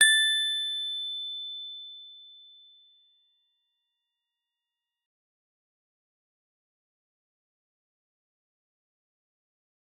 G_Musicbox-A6-f.wav